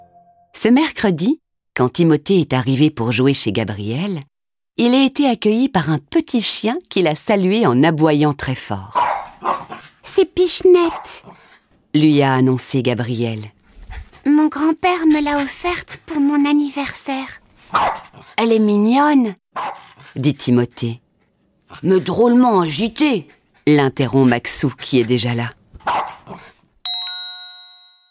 0% Extrait gratuit Timoté veut un animal de Mélanie Combes , Emmanuelle Massonaud Éditeur : Lizzie Paru le : 2023-05-11 Quel animal pour Timoté ?Timoté est un petit lapin qui, au fil des livres audio, partage sa vie avec ses petits lecteurs. Il aimerait tellement lui aussi avoir un chien comme ses copains !